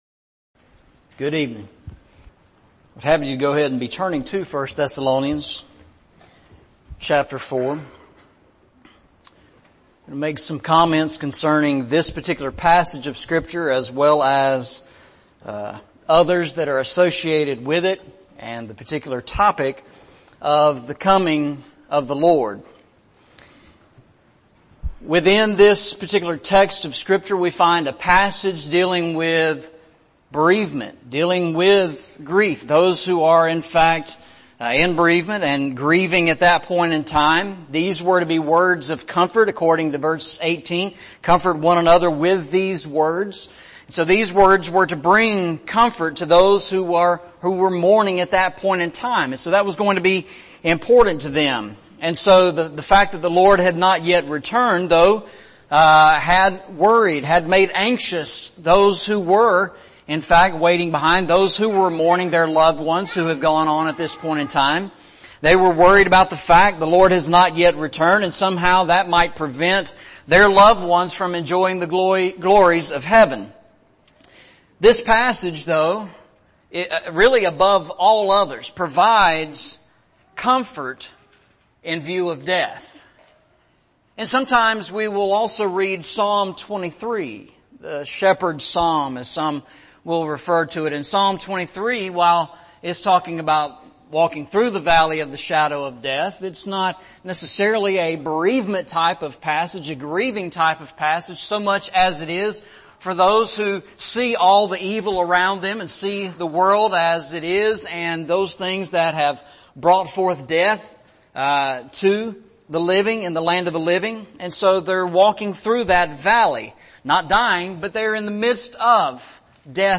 Eastside Sermons Service Type: Sunday Evening Preacher